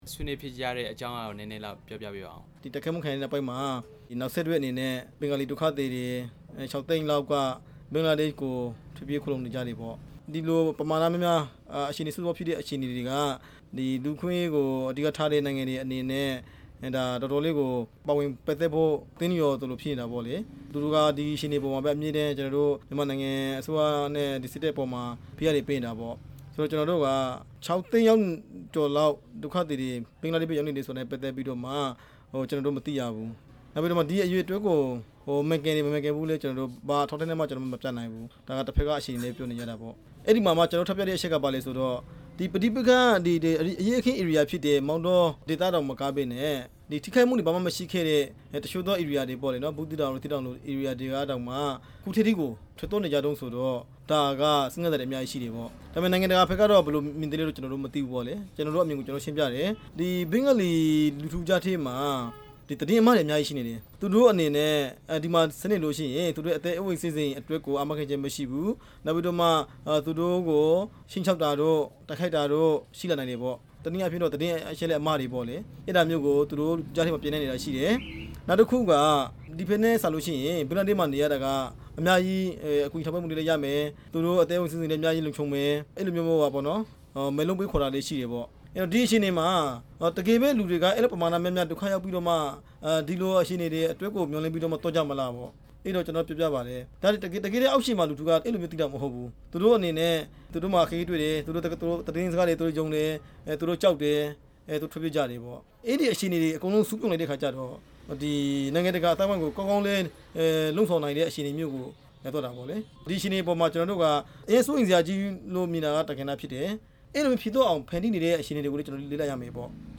အမေရိကန် သံအမတ်နဲ့ လွှတ်တော်အမတ်တွေ ANP ခေါင်းဆောင်တွေနဲ့ တွေ့ဆုံမှုအကြောင်း မေးမြန်းချက်